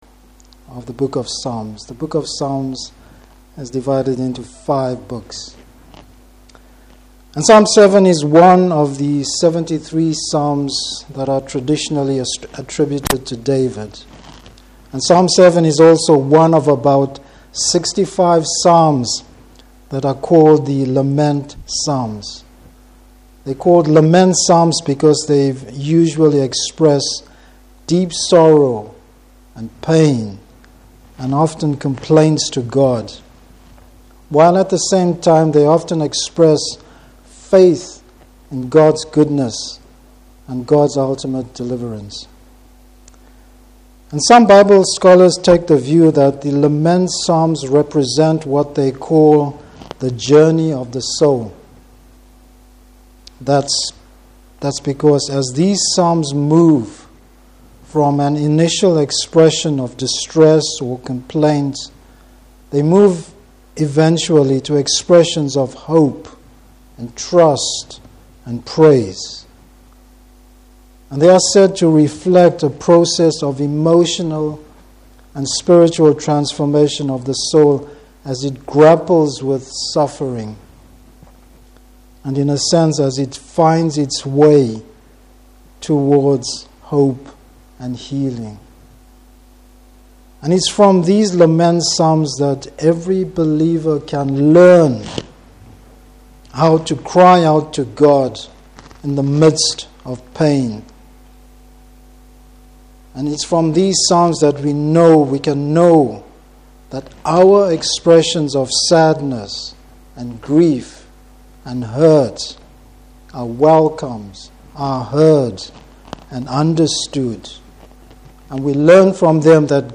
Service Type: Evening Service The aim of the Christian.